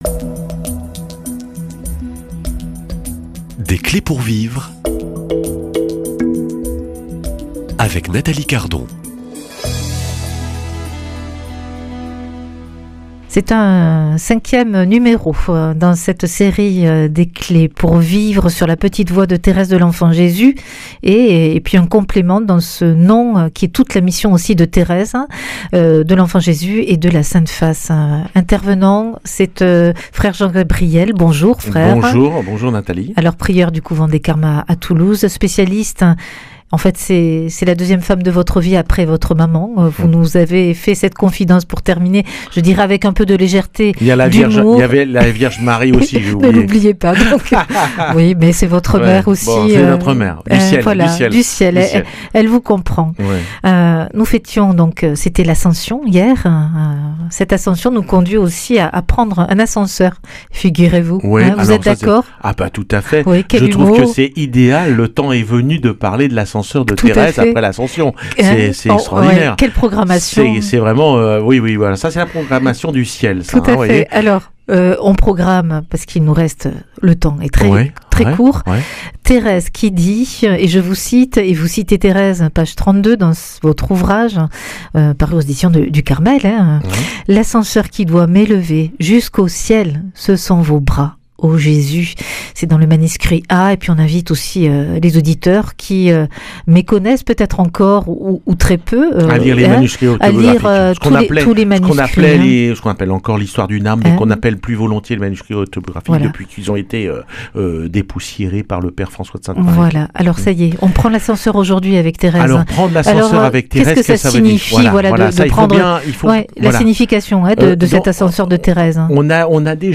Invité